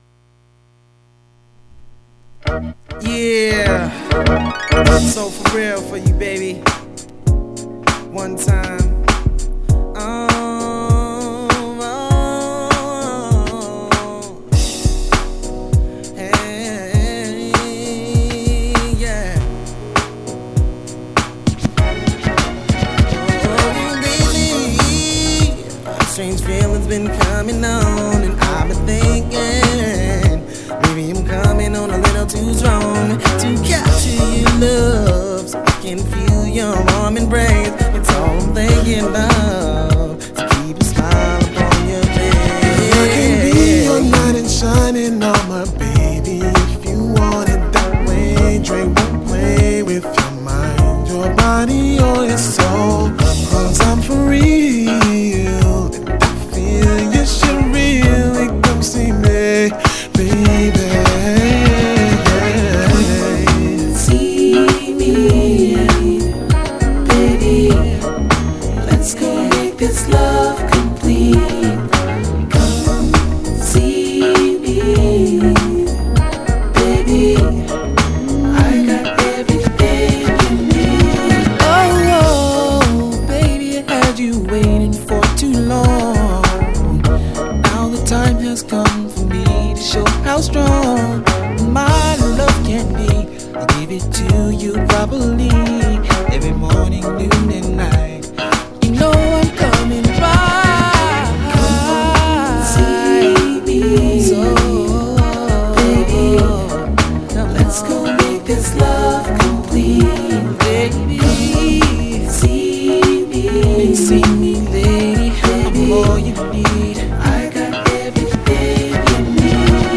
VG+ *B面に目立つスレありますが音には影響ありません